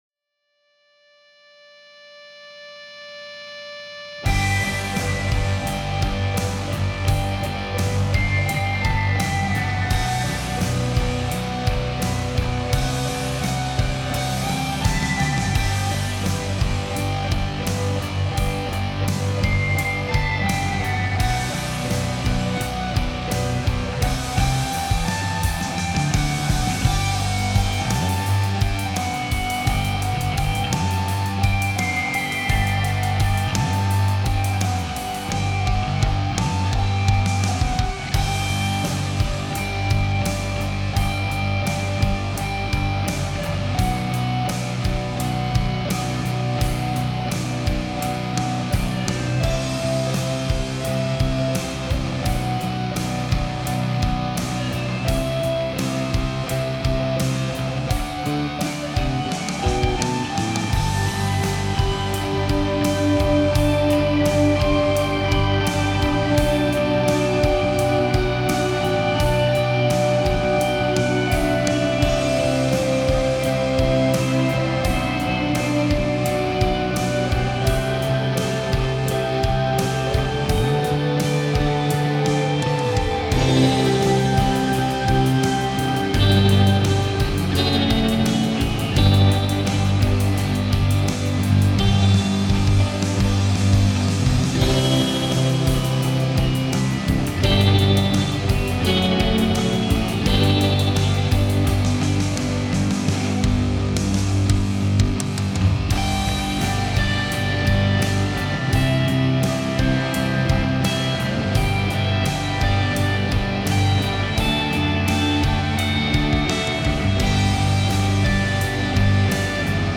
ゆったりとした曲なので、初回にはふさわしくないかなとも思いましたが、すでに活動中の歌い手さんは「セトリに元気で明るい曲が多いので、ゆったりした箸休め的な曲が欲しいな」と思ってる方もいるかもですね。
カラオケ音源